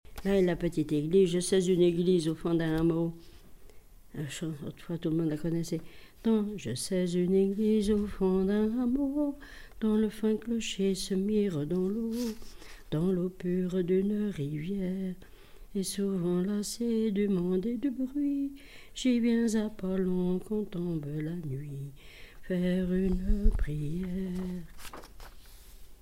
Genre laisse
témoignage et chansons
Pièce musicale inédite